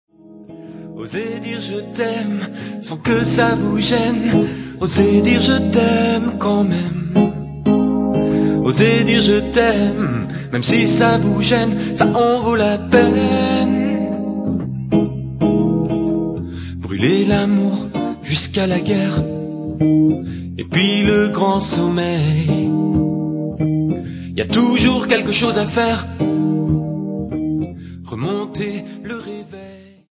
Une idée cadeau pour les musiciens et les non musiciens amoureux de guitare rock !
Téléchargez librement les chansons d’amour écrites et interprétées par